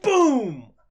DanisRace/sfx/voices/angry_pursuit/hit_04.ogg at main